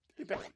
外星人的声音
描述：一个奇怪的声音，我很久以前在玩凝固器的时候疯了
标签： 外星人声音 外星人 快速 BOOP 凝块 蜂鸣
声道立体声